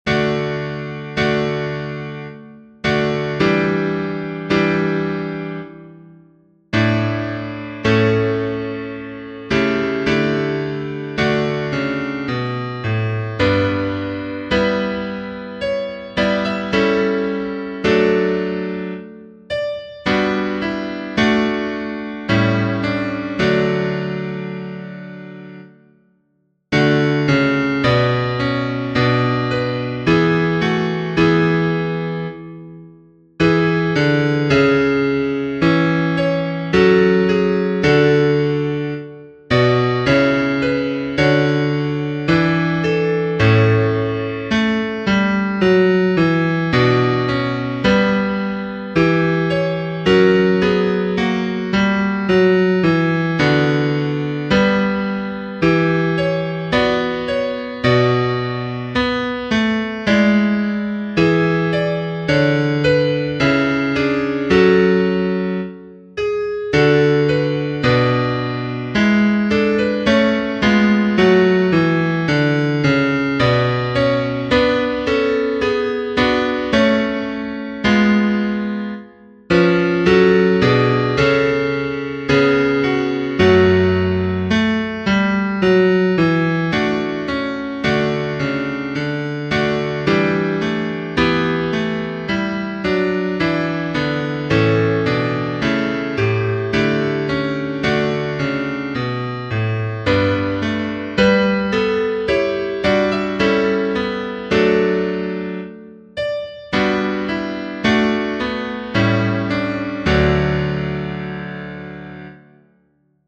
LASCIA_CHIO_PIANGA_tutti.mp3